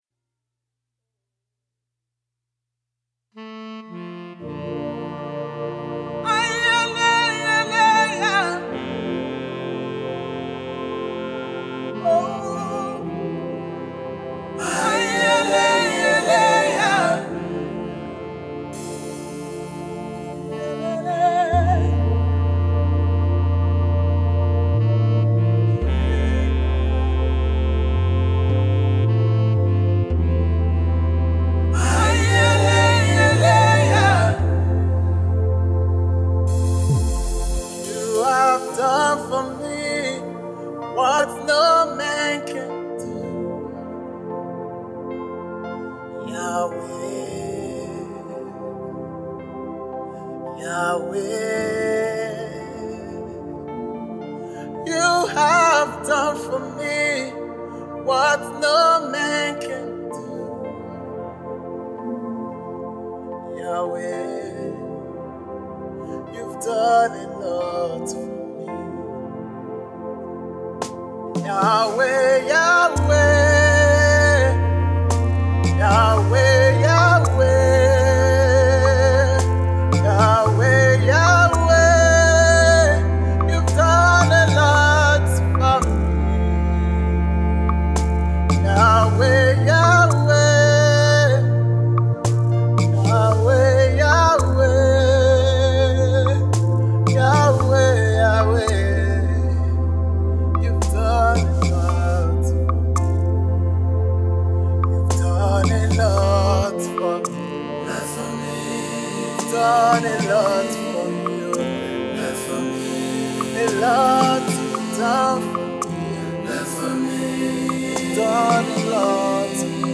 a fast-rising Nigeria gospel music minister
lively and refreshing
It’s an everyday thanksgiving song.